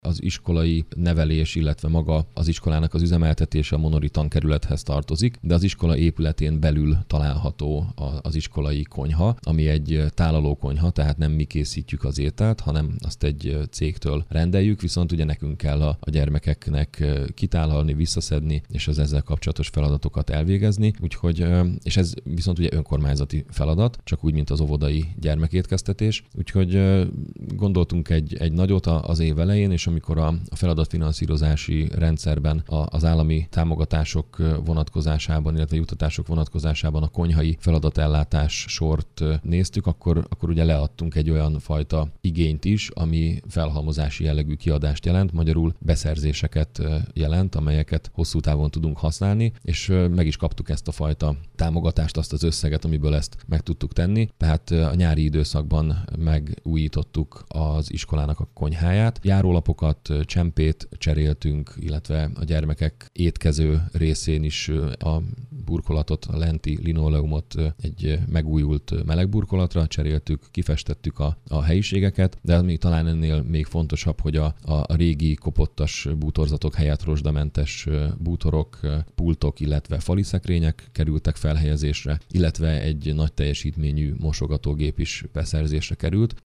Mészáros Sándor polgármester elmondta, kicserélték a bútorokat, új konyhaszekrényeket vásároltak, illetve járólapokat és csempét cseréltek.